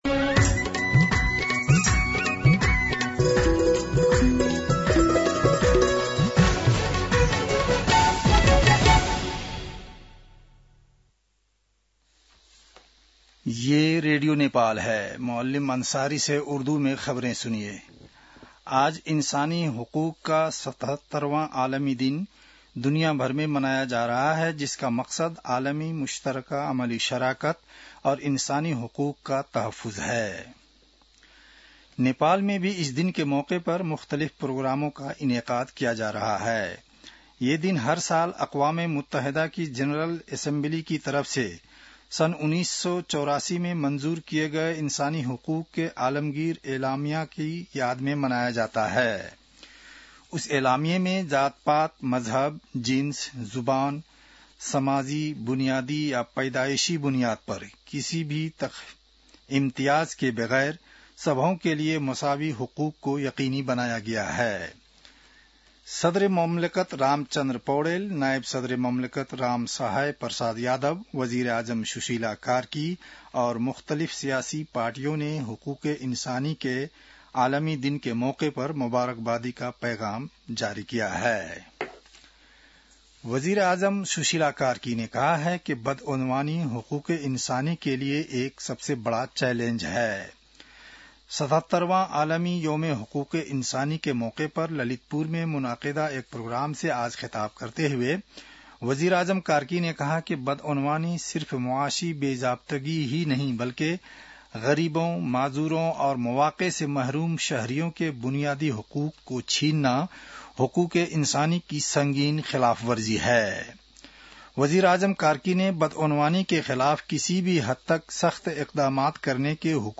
उर्दु भाषामा समाचार : २४ मंसिर , २०८२